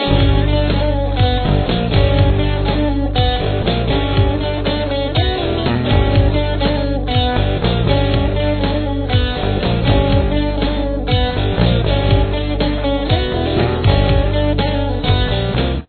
Main Riff
The main riff has 2 guitar parts.